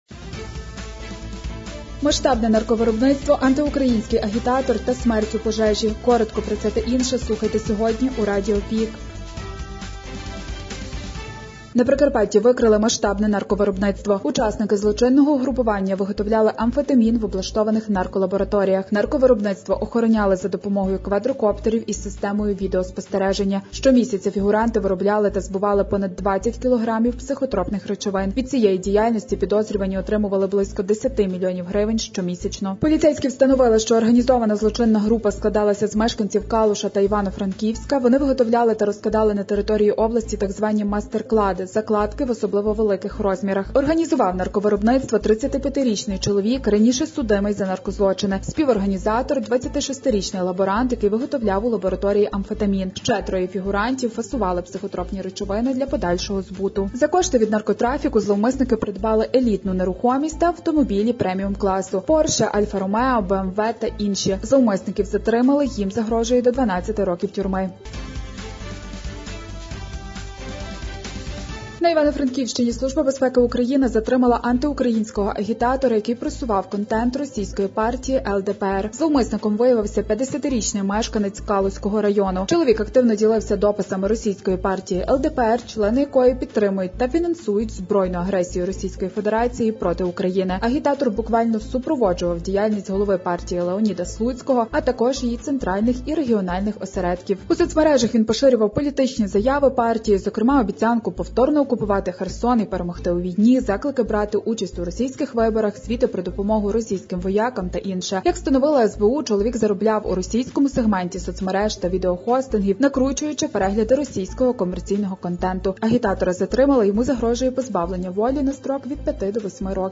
Радіо ПІК: головні новини Прикарпаття та України за третє квітня (ПРОСЛУХАТИ)
Пропонуємо Вам актуальне за день у радіоформаті.